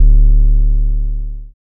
808 (Metro).wav